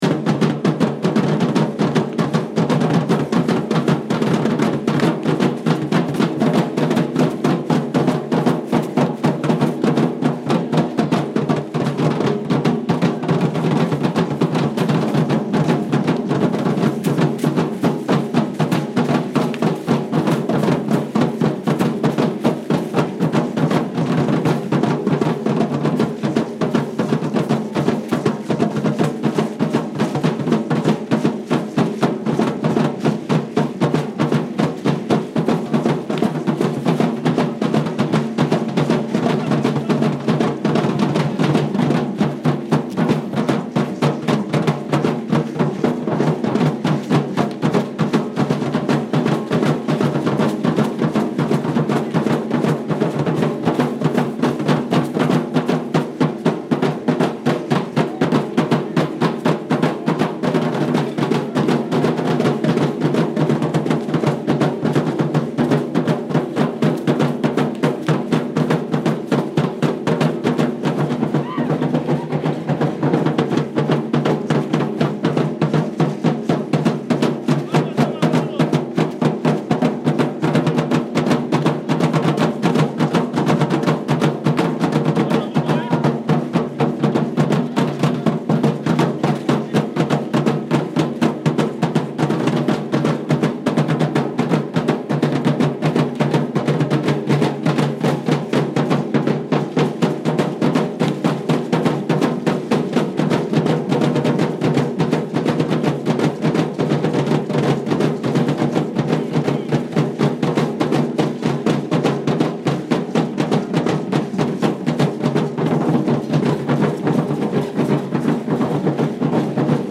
Day of the Death at Xoxocotlán cemetery. Families gather around thumbs. Stereo 48kHz 24bit.